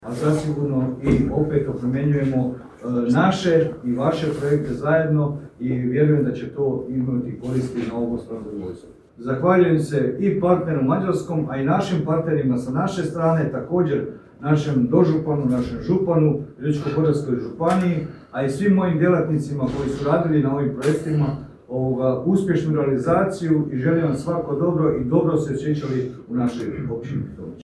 Općina Pitomača zajedno sa Mecsekerdo Zrt. Turističkom zajednicom općine Pitomača i Javnom ustanovom za upravljanje zaštićenim dijelovima prirode i ekološkom mrežom Virovitičko-podravske županije održala je početnu konferenciju projekta „BRIDGE”.
načelnik Općine Pitomača Željko Grgačić koji je pozdravio sve nazočne: